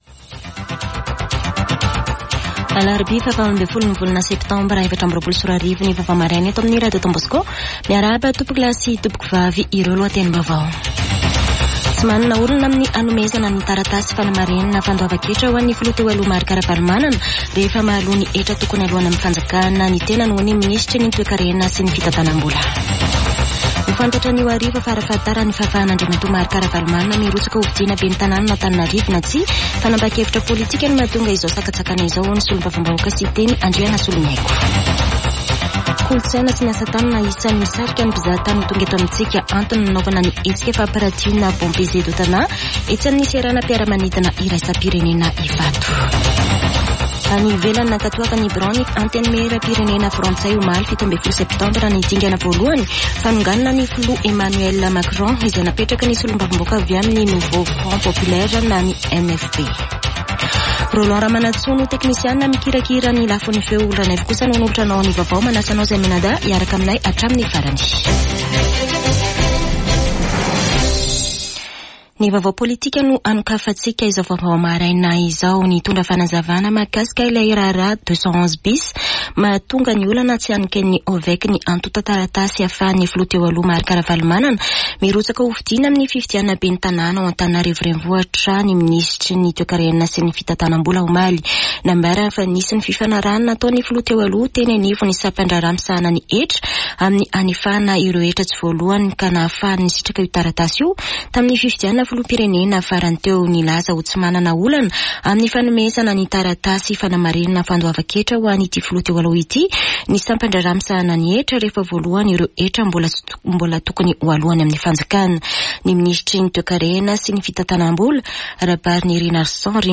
[Vaovao maraina] Alarobia 18 septambra 2024